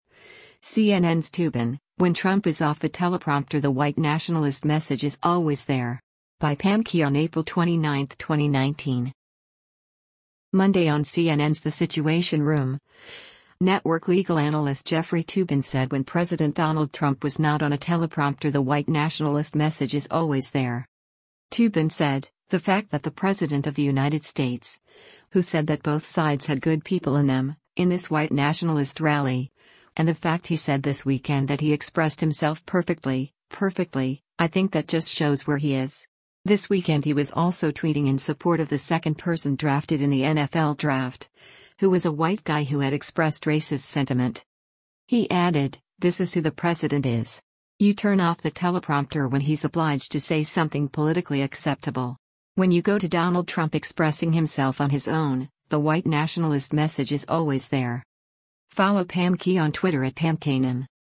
Monday on CNN’s “The Situation Room,” network legal analyst Jeffrey Toobin said when President Donald Trump was not on a teleprompter “the white nationalist message is always there.”